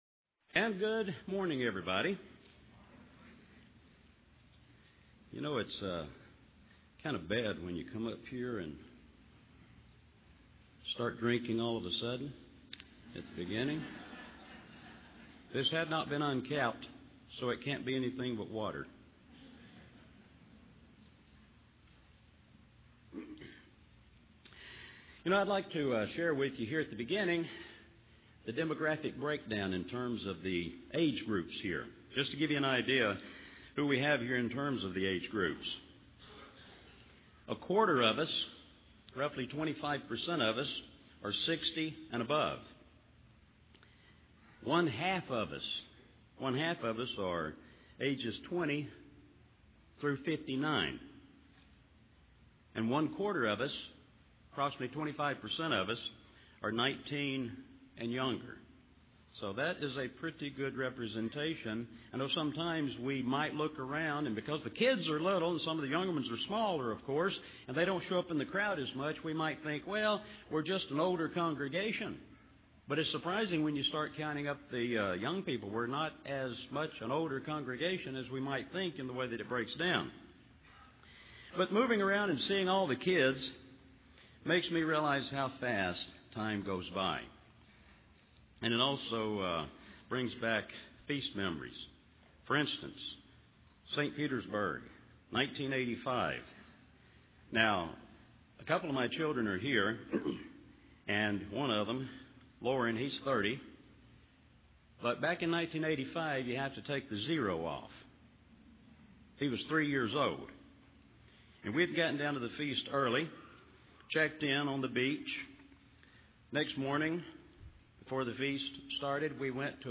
This sermon was given at the Panama City Beach, Florida 2012 Feast site.